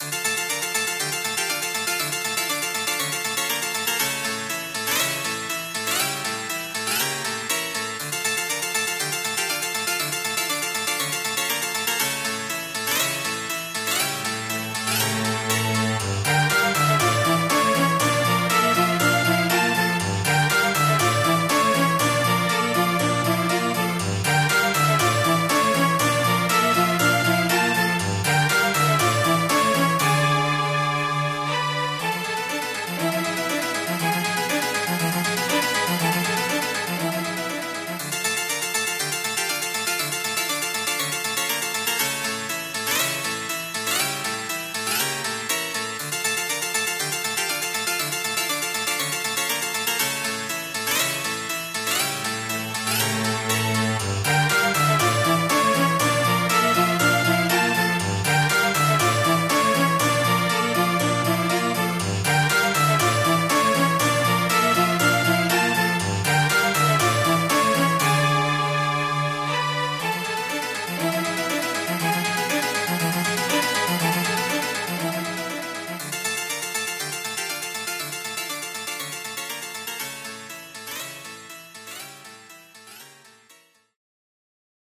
cette musique est particulièrement prenante et envoutante